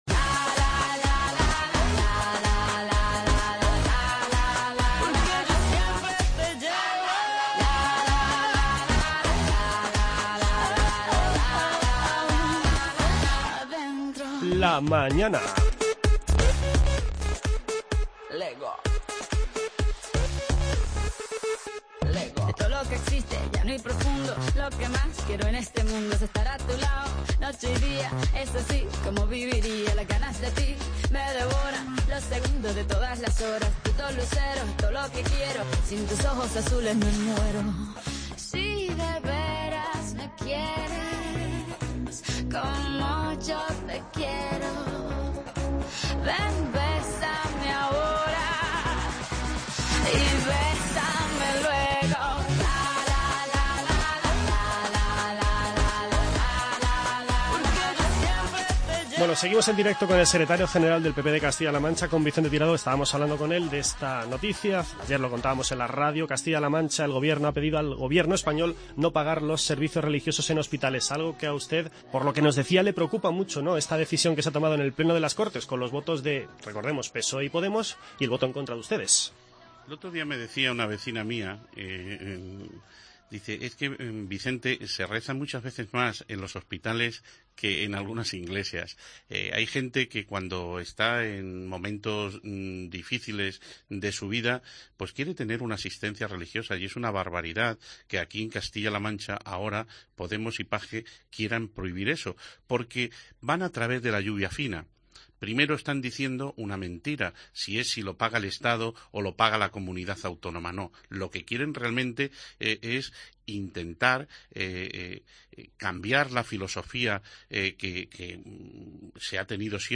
Escuche la entrevista con Vicente Tirado, secretario general del PP de Castilla-La Mancha.